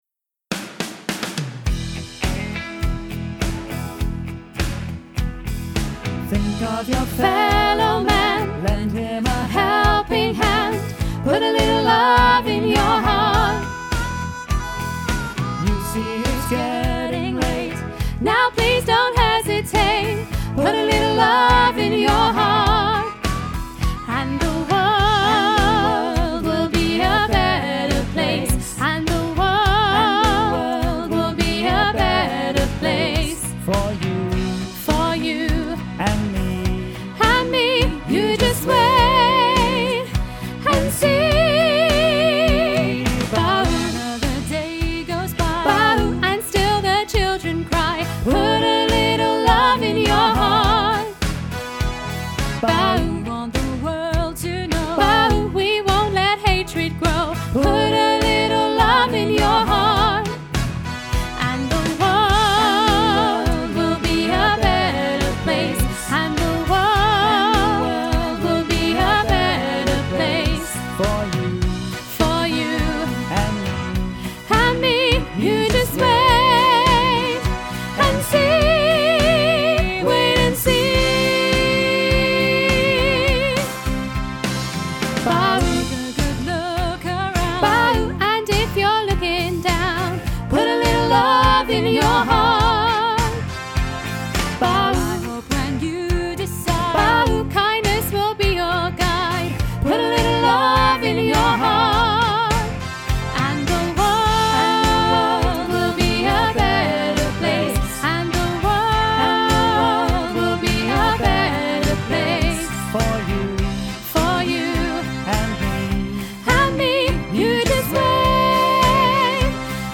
put-a-little-love-soprano-half-mix.mp3